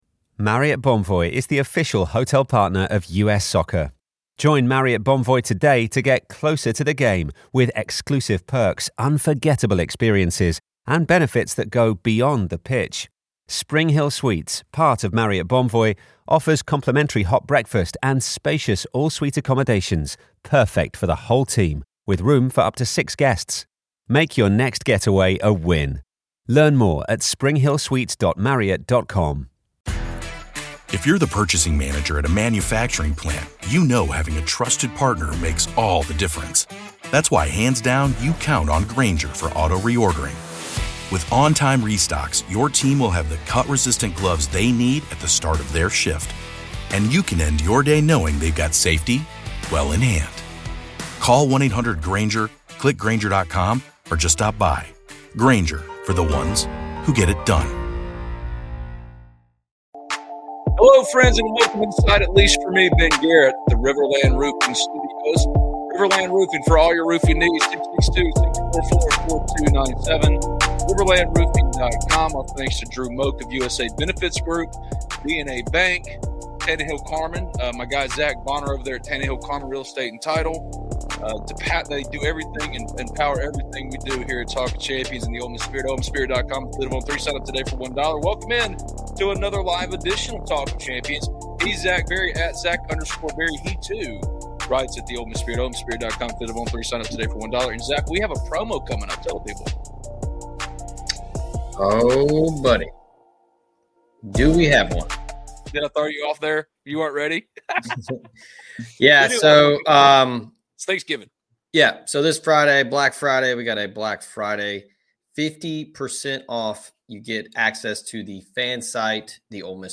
LIVE for your lunch break and on Thanksgiving Eve